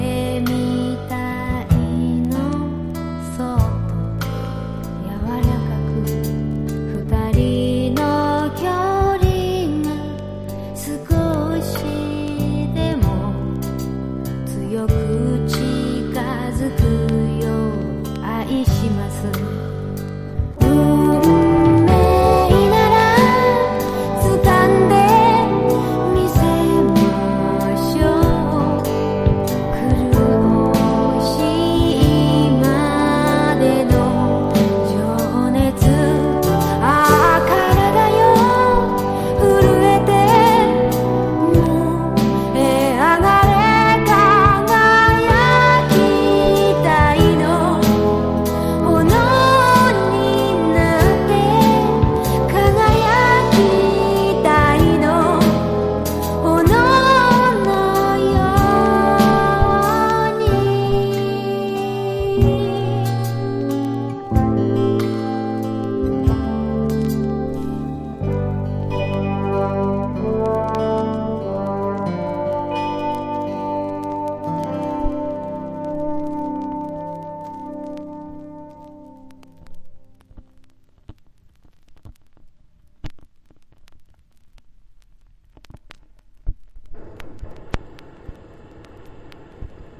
ドラマ主題歌だったA面のメロウネス・バラードも◎。
FOLK# CITY POP / AOR# 和モノ